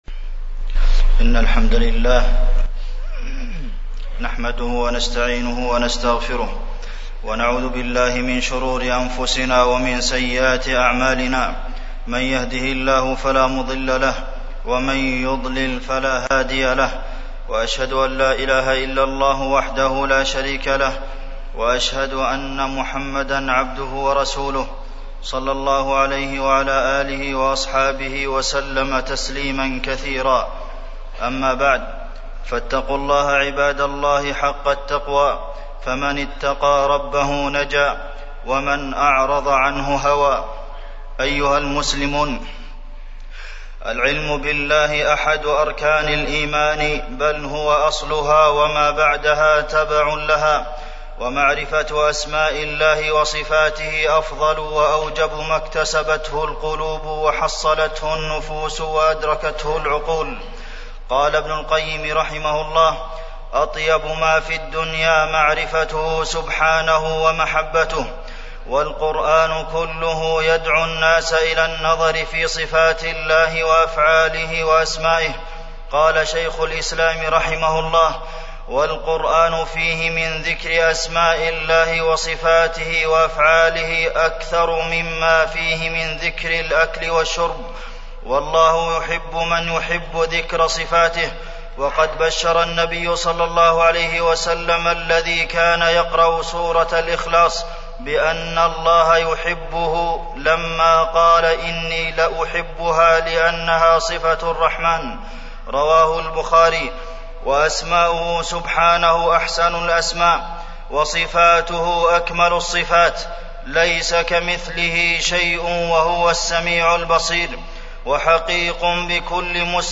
تاريخ النشر ٢٣ شوال ١٤٢٦ هـ المكان: المسجد النبوي الشيخ: فضيلة الشيخ د. عبدالمحسن بن محمد القاسم فضيلة الشيخ د. عبدالمحسن بن محمد القاسم أسماء الله وصفاته